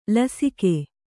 ♪ lasike